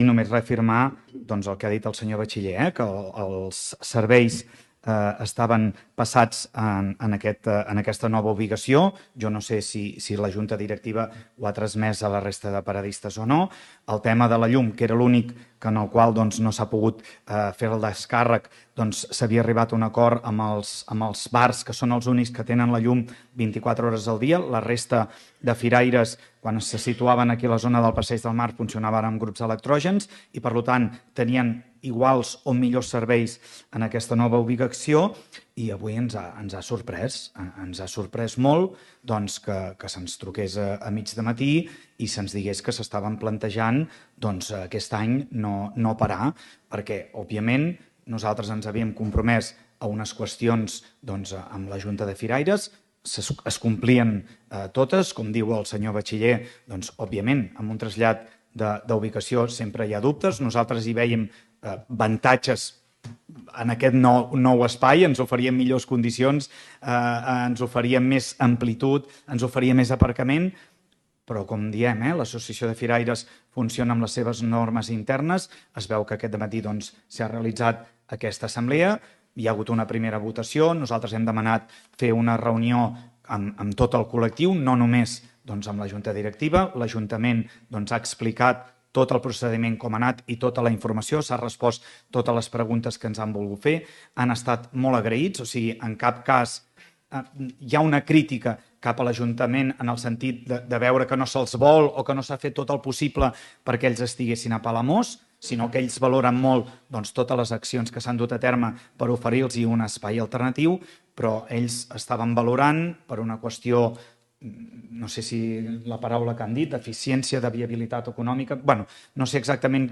En el ple d’aquest mes de juny, que s’ha celebrat aquest dimarts, l’alcalde de Palamós, Lluís Puig, explicava que tot i la incertesa dels firaires, els serveis en aquesta nova ubicació estan assegurats.